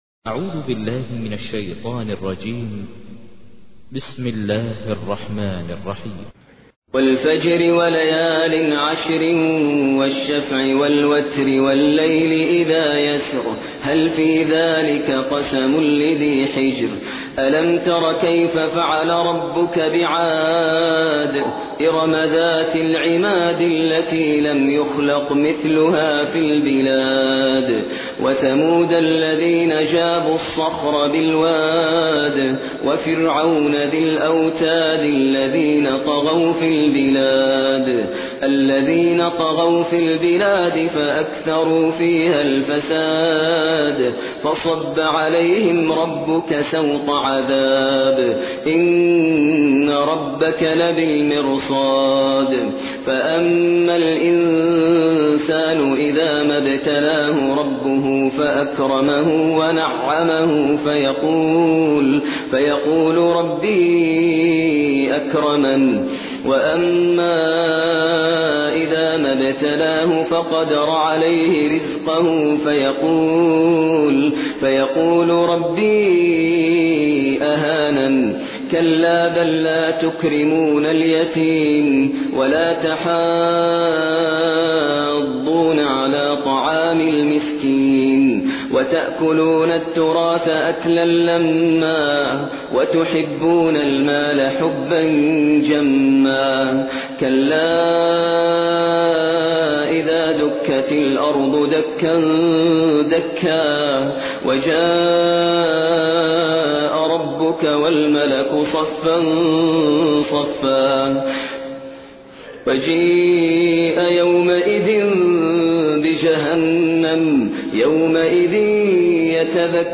تحميل : 89. سورة الفجر / القارئ ماهر المعيقلي / القرآن الكريم / موقع يا حسين